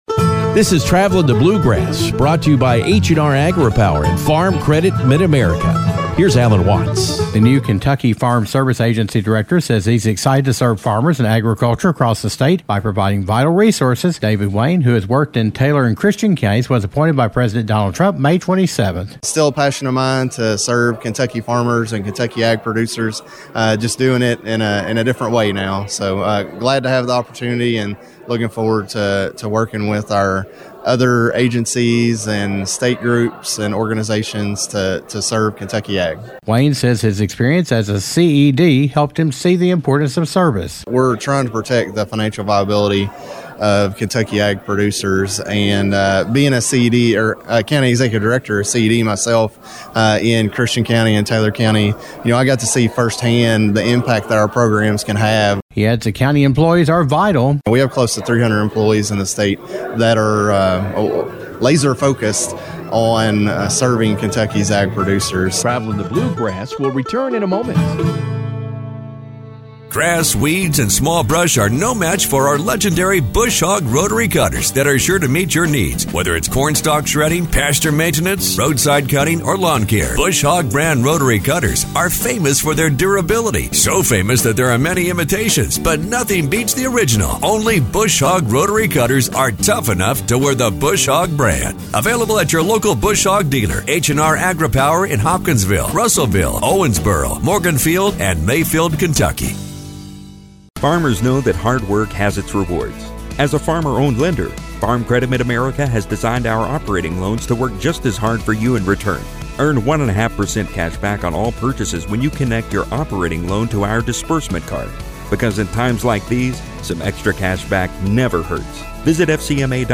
New Kentucky Farm Service Agency Director David Wayne says the agency he is leading continues to be committed to serving farmers across the state. Wayne, who was appointed by President Donald Trump in late May, talks about the post and provides some reminders about upcoming deadlines.